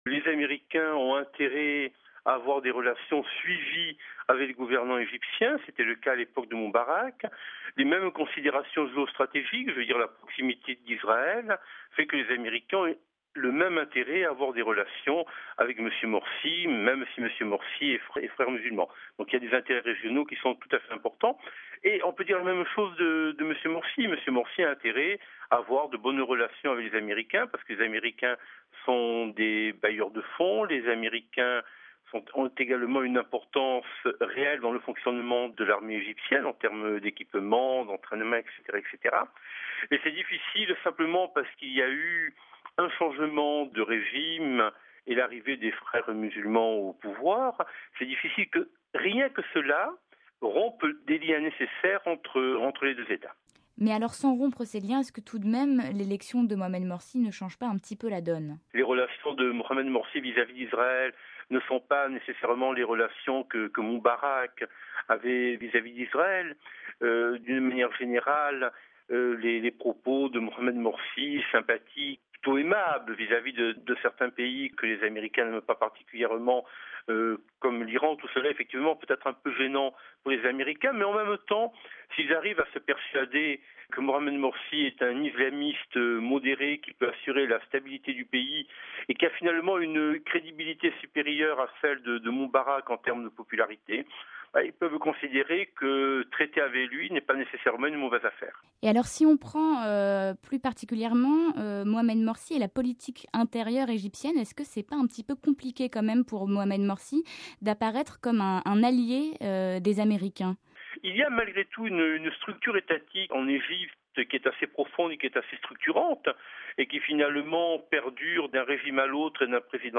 Dossier : vers des relations entre l'Egypte et les Etats-Unis toujours stables